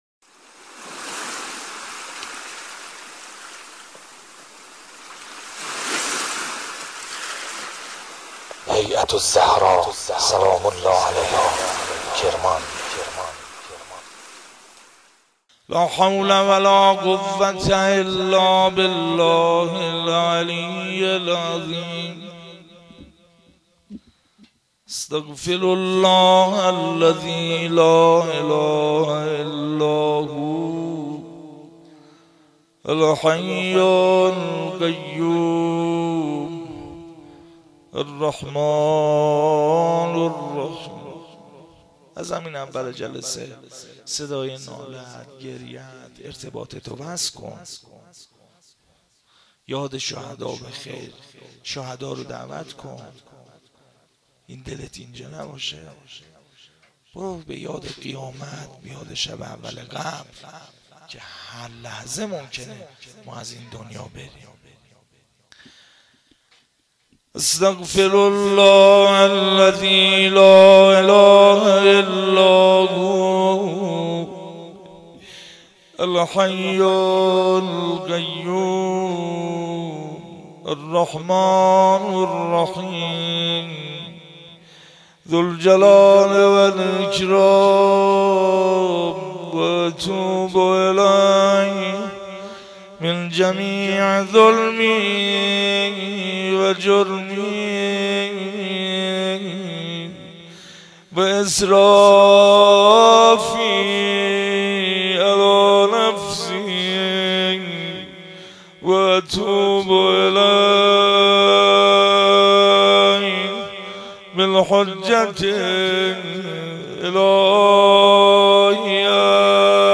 جلسه هفتگی14تیرماه1396
مناجات